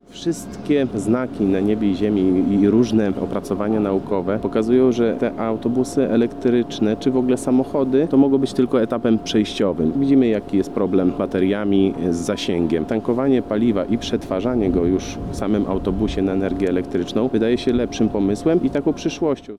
Natomiast o tym, dlaczego akurat autobusy wodorowe, usłyszymy od Marcina Dmowskiego, burmistrza Miasta Świdnik: